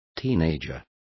Complete with pronunciation of the translation of teenagers.